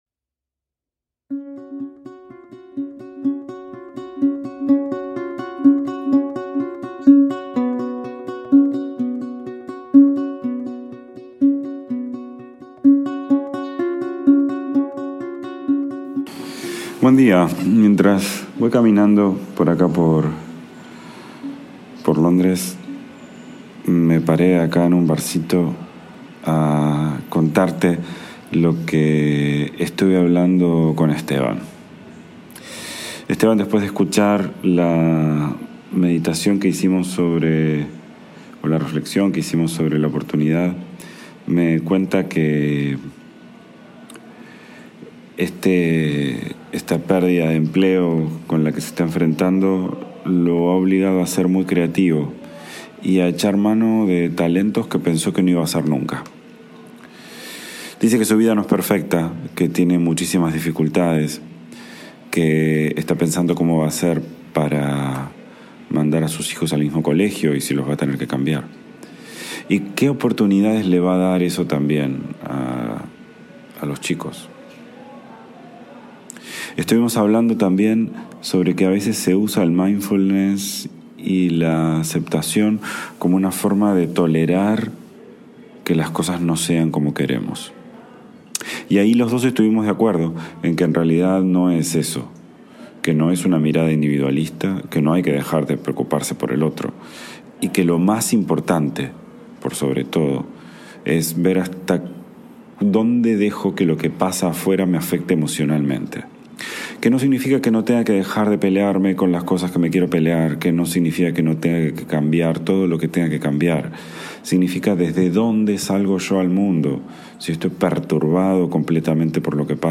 IMPORTANTE: Esta serie fue grabada durante una época de muchos viajes, directamente en mi teléfono móvil. La calidad del audio no está a la altura de lo que escucharás en las siguientes series y episodios.